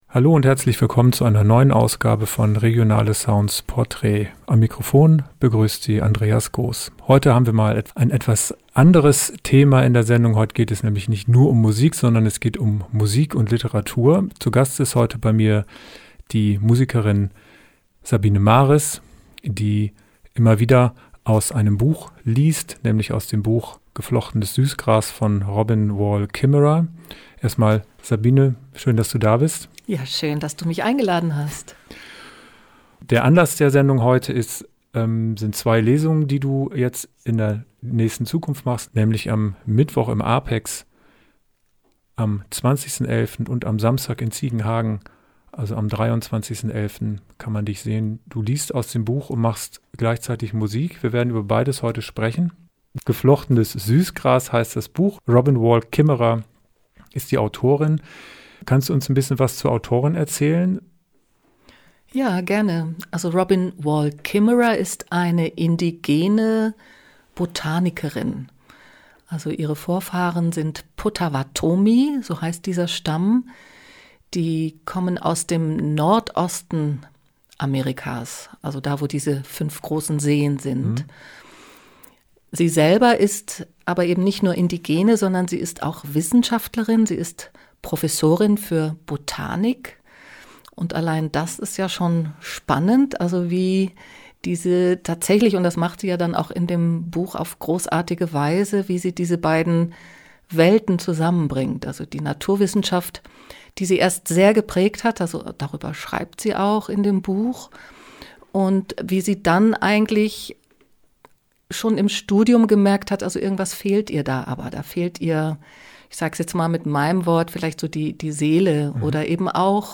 musikalische Lesung